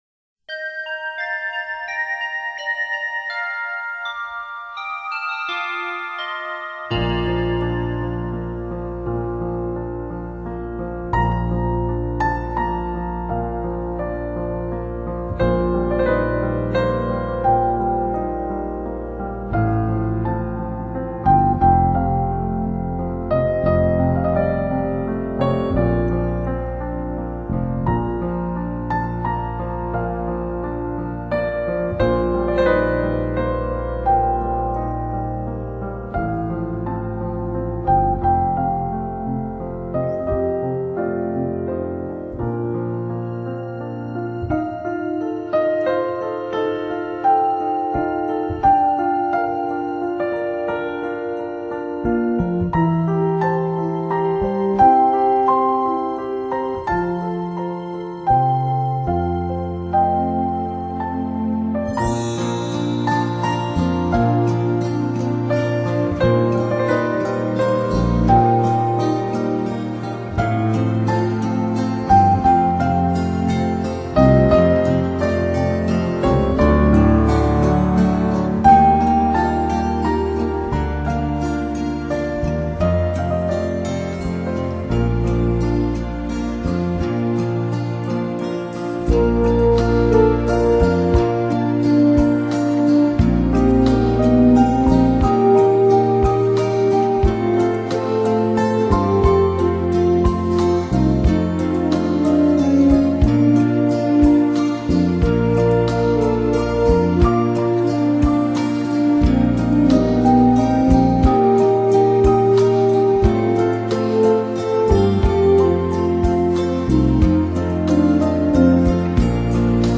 音乐类型：纯音乐